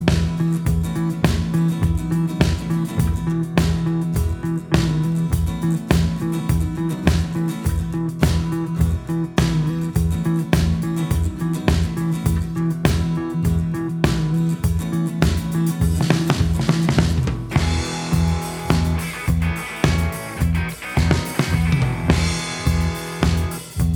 No Lead Guitar Rock 3:39 Buy £1.50